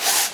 Clothing Rub Sound
household
Clothing Rub